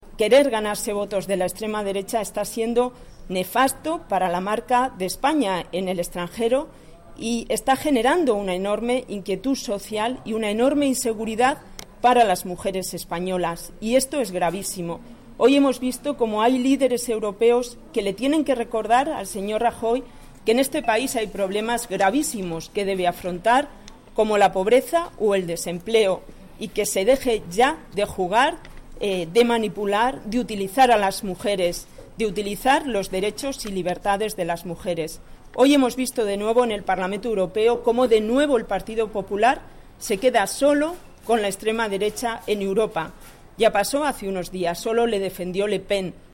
Declaraciones de Puri Causapié sobre la reforma del aborto 16/01/2014